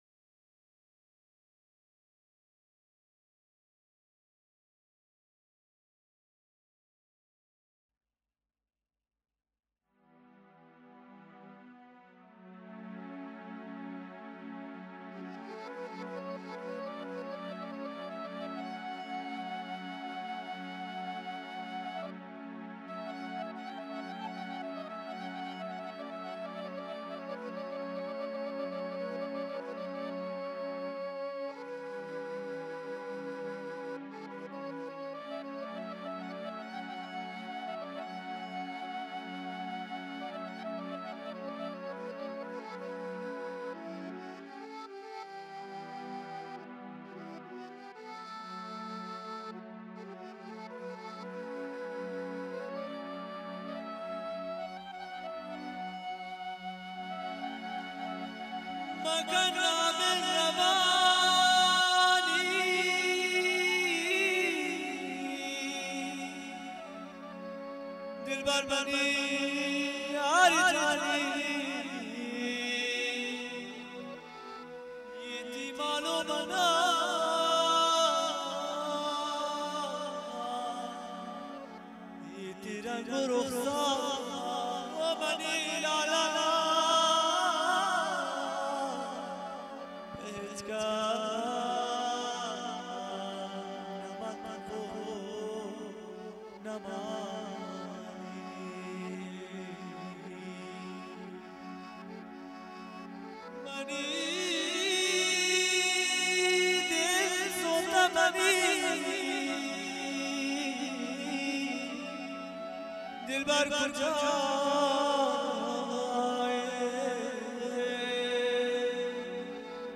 آهنگ بلوچی